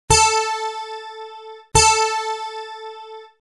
Pst... Klicka på tonerna så kan du stämma din gitarr efter ljudet!